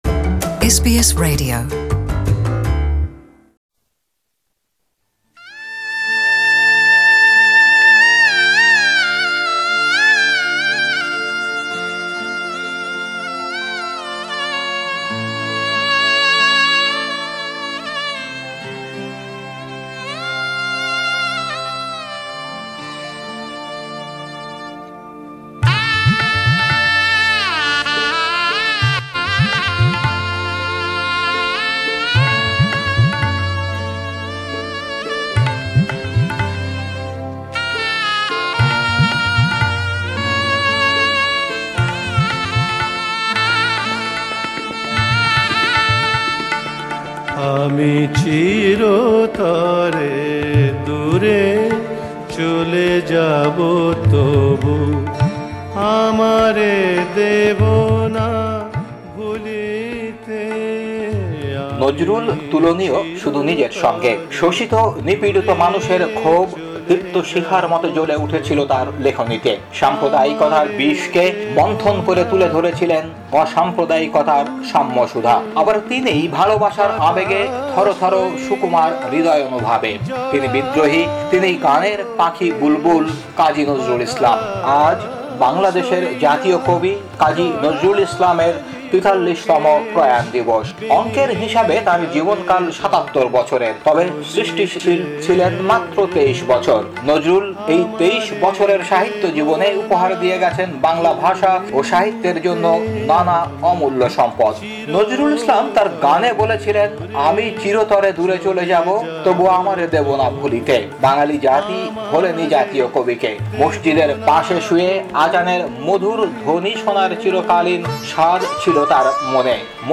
Listen to this report (in Bangla) in the audio player above.